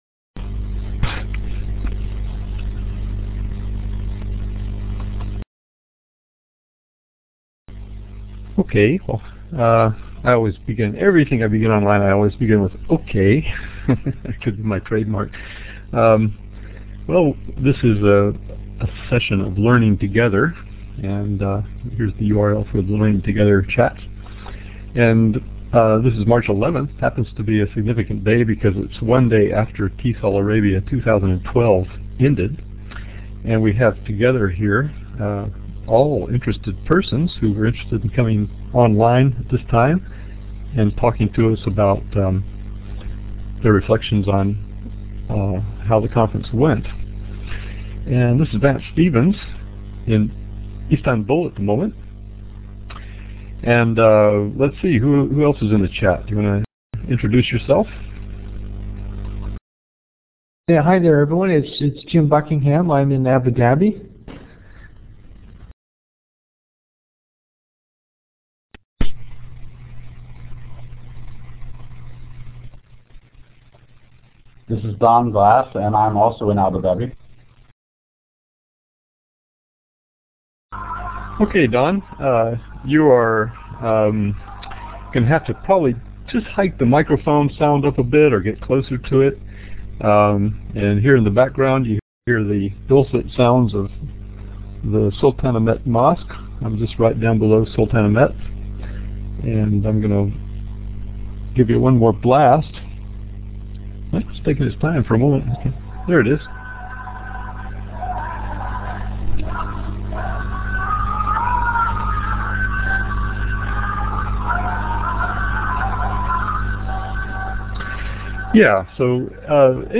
presented live at TESOL Arabia, Dubai Women’s College, Room 1042